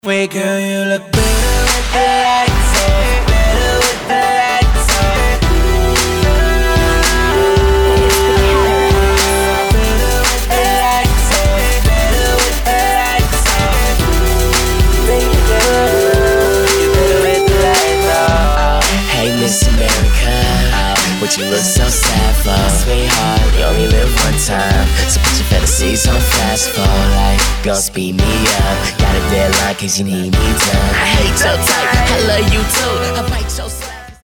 Americké hip-hopové duo